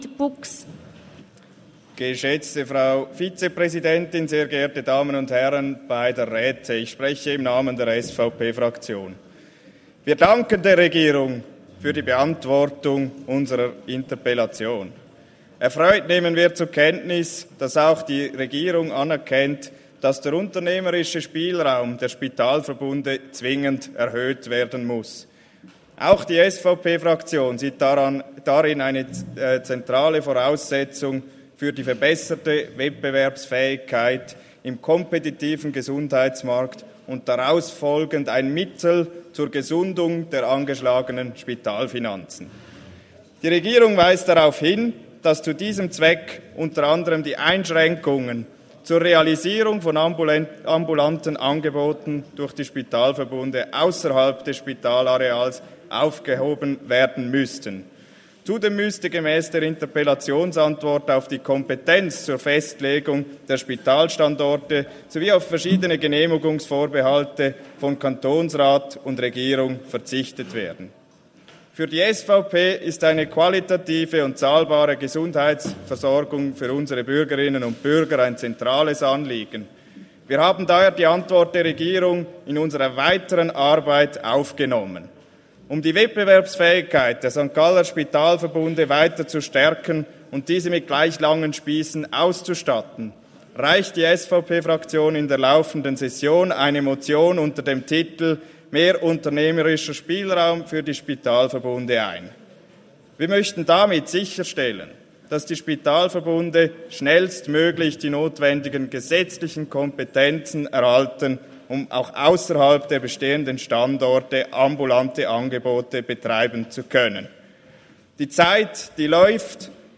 Session des Kantonsrates vom 19. bis 21. September 2022